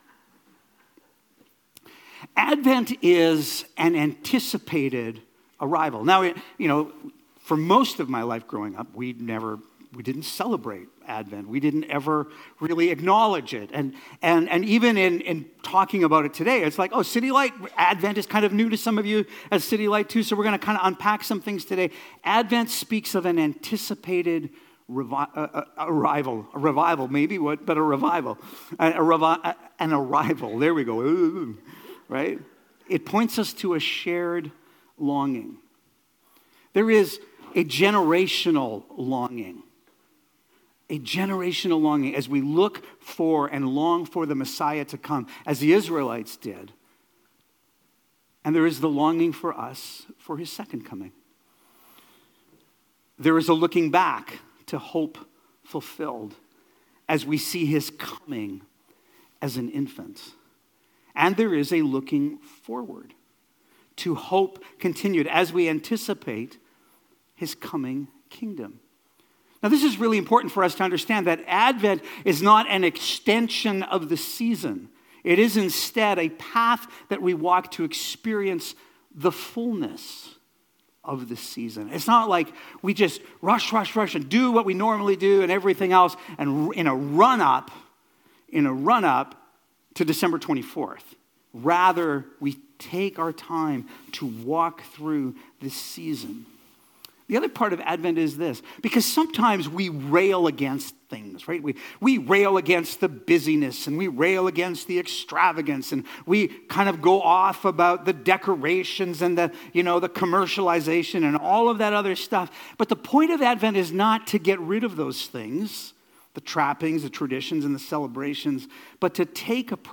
Sermons | City Light Church